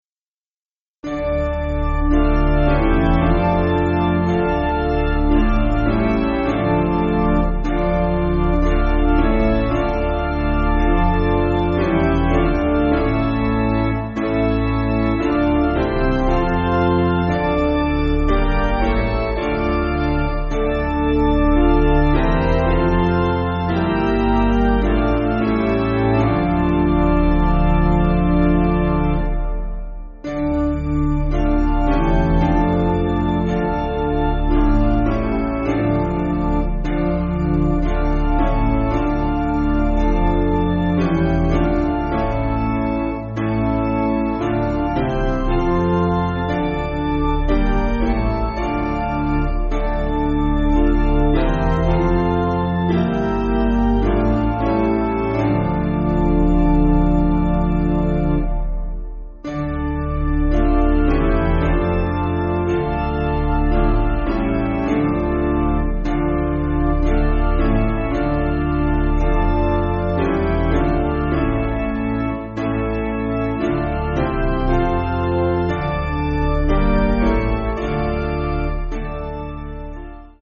Basic Piano & Organ
(CM)   4/Dm